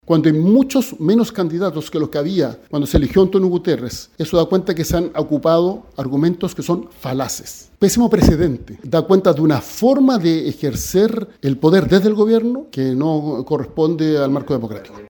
En entrevista con Radio Bío Bío, el exministro del Interior del gobierno de Gabriel Boric, Álvaro Elizalde, se cuadró también con Bachelet, trató de falaces los argumentos de La Moneda para quitar el apoyo y acusó persecución política en la investigación del Ministerio de Relaciones Exteriores.